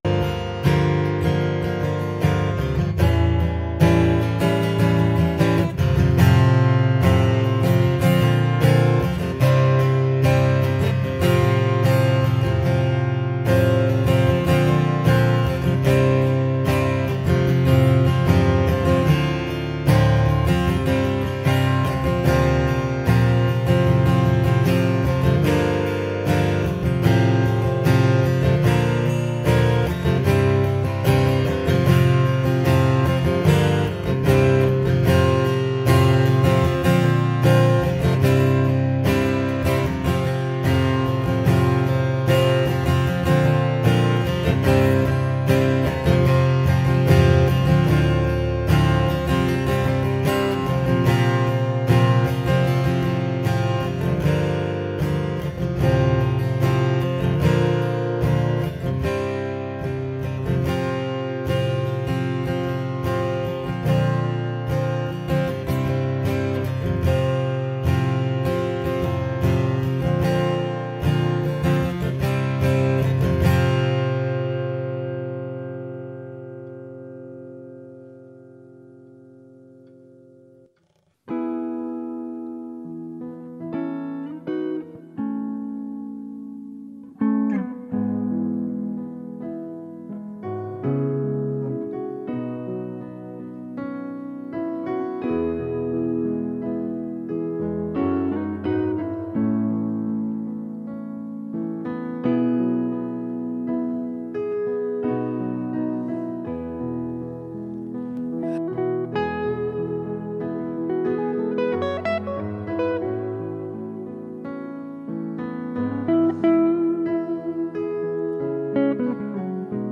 Passage: Matthew 13:1-23 Service Type: Sunday Morning